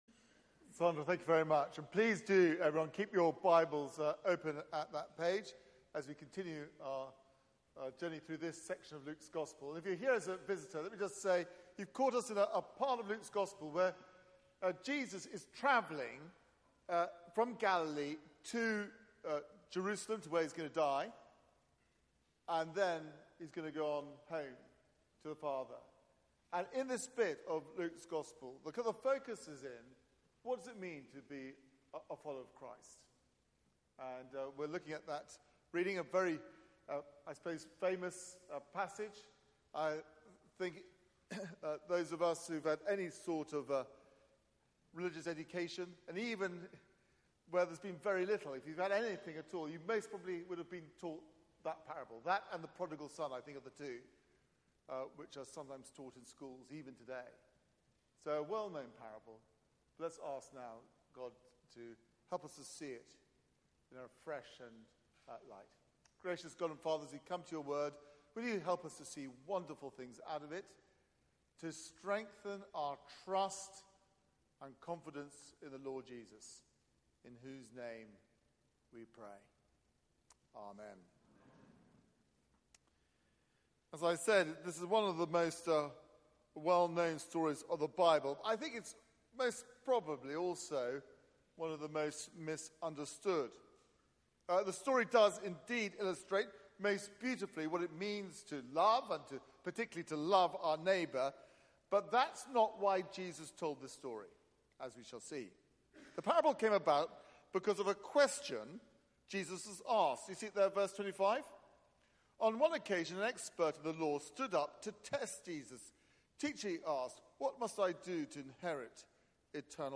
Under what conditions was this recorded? Media for 6:30pm Service on Sun 03rd Mar 2019 18:30 Speaker